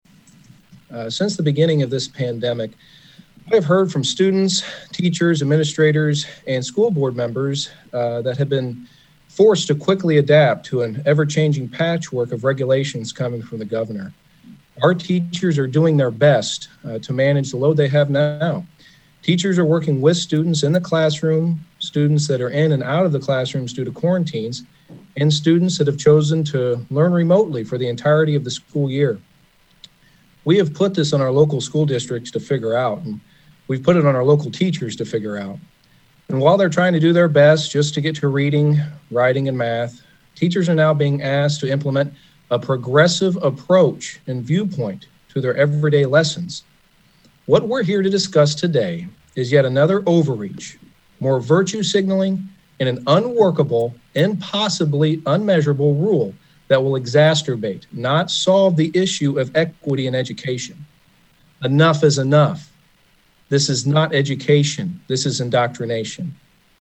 Illinois House Republicans held a press conference Monday morning to discuss their opposition to the new standards for educators, adopted by the Illinois Board of Education (ISBE) that deal with culturally responsive teaching.
State Representative Adam Niemerg (R-Dieterich) took part in the press conference.